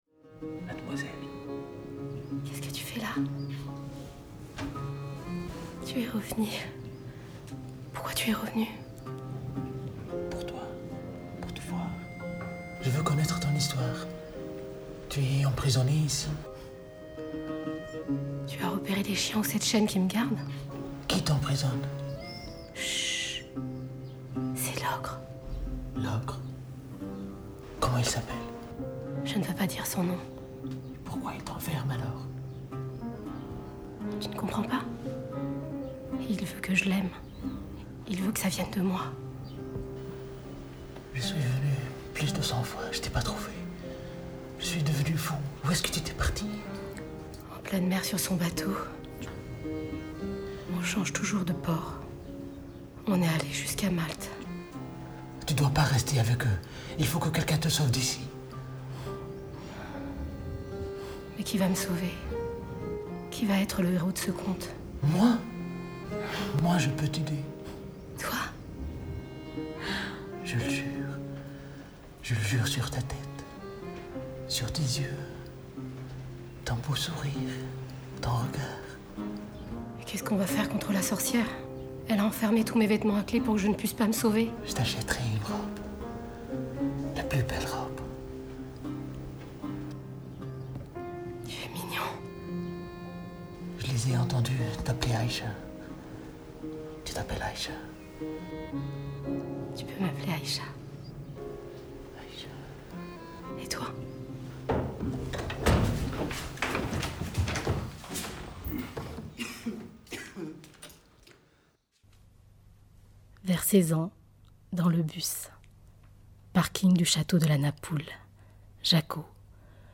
Démo voix